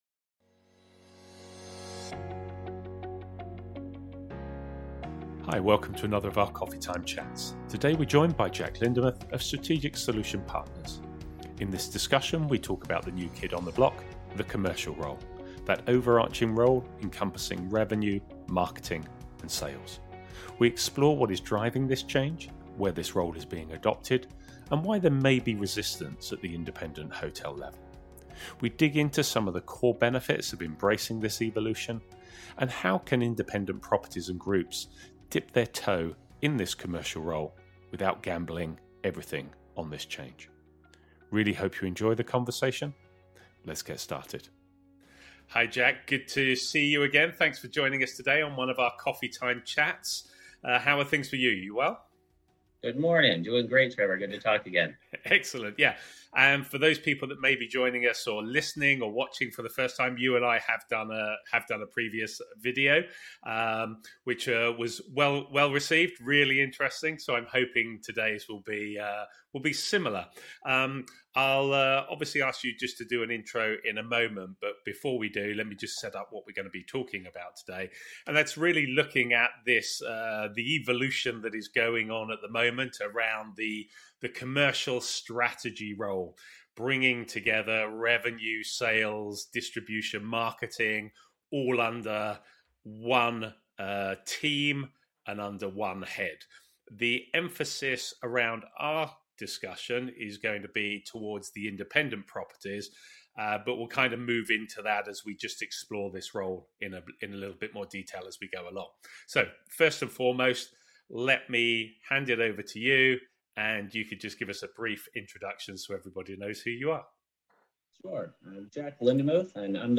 Welcome to another of our Coffee Time chats.